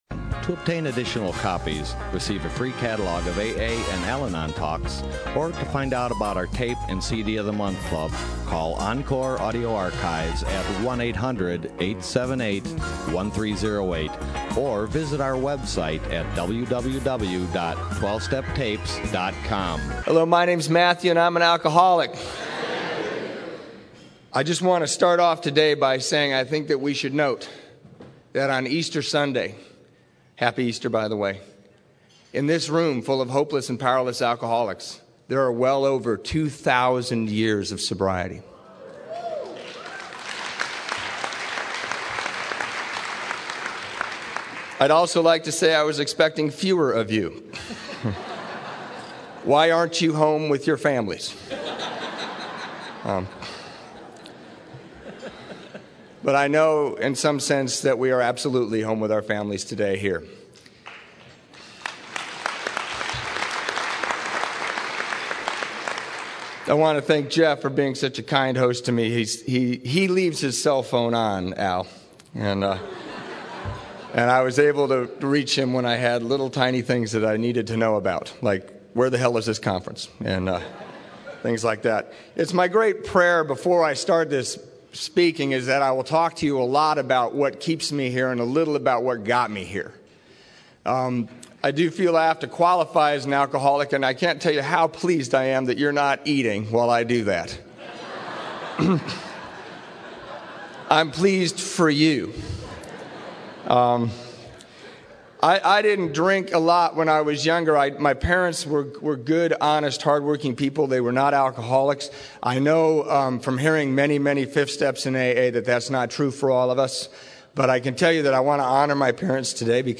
San Diego Spring Roundup 2007